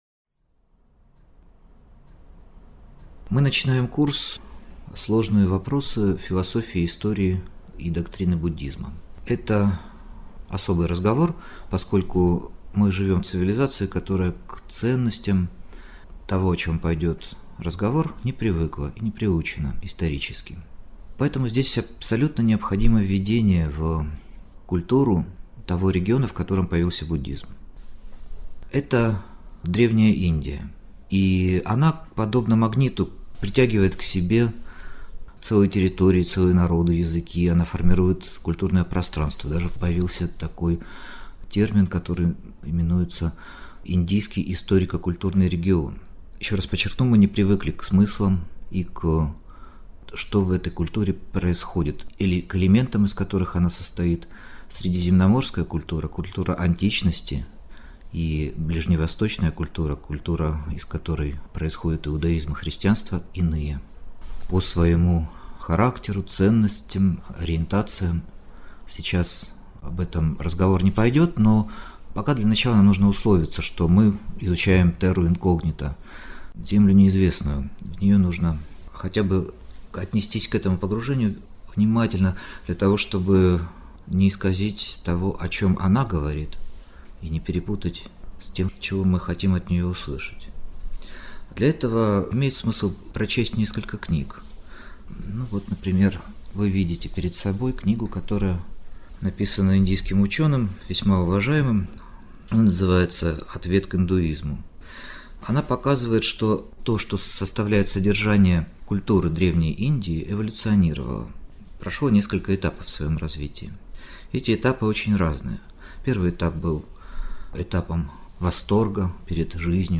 Аудиокнига Религия Древней Индии: Веды, Упанишады, шраманы | Библиотека аудиокниг